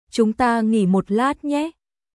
Chúng ta nghỉ một lát nhé?少し休憩しませんか？チュンタ ンギー モッ ラット ニェ？